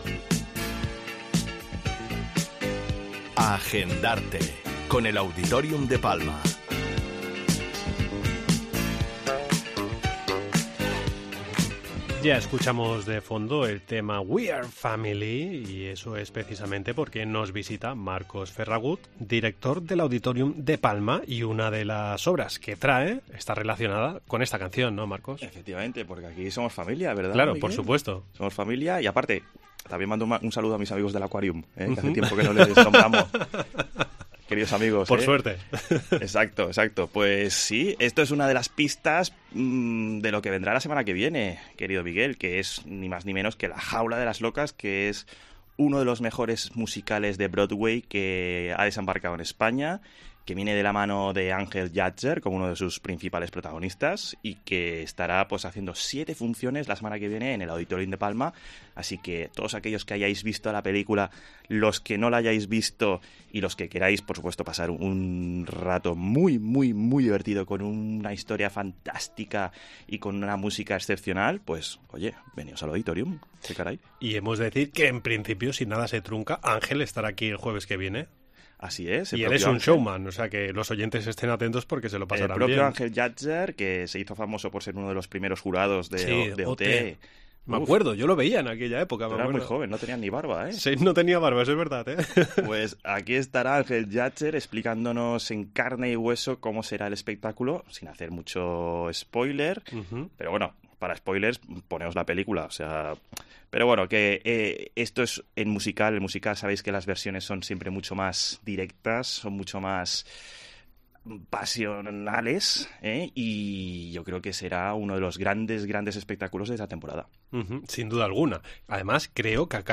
Entrevista en 'La Mañana en COPE Más Mallorca', jueves 19 de enero de 2023.